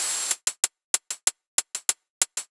Index of /musicradar/ultimate-hihat-samples/95bpm
UHH_ElectroHatC_95-02.wav